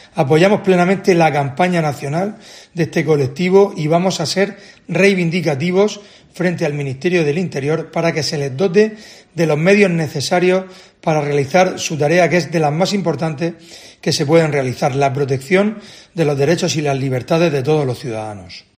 Fulgencio Gil, alcalde de Lorca